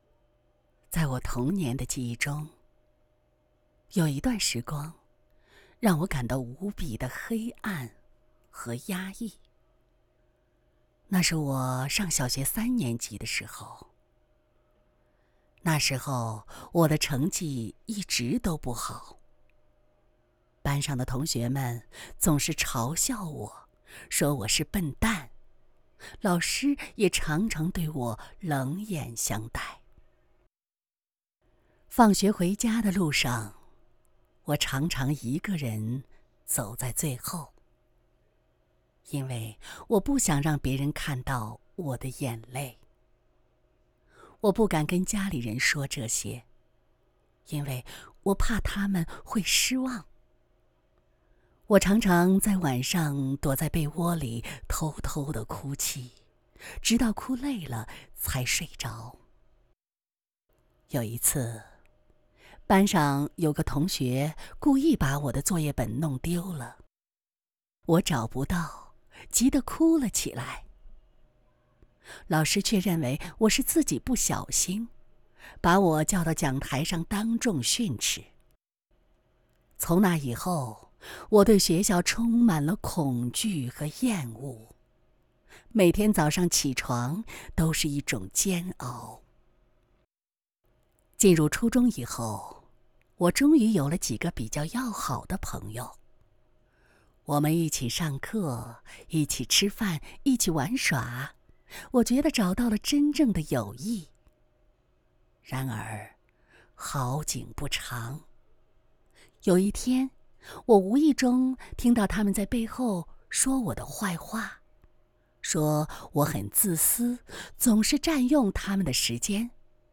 Chinese_Female_001VoiceArtist_40Hours_High_Quality_Voice_Dataset
Sad Style Sample.wav